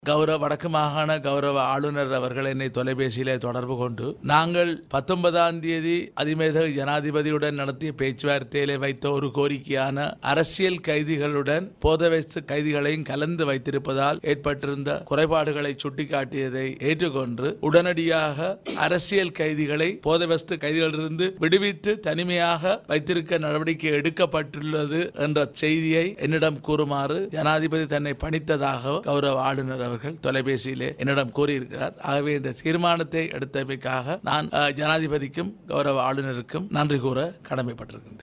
இதுதொடர்பில் தொடர்ந்தும் உரையாற்றிய மாகாண சபை உறுப்பினர் எம்.கே.சிவாஜிலிங்கம்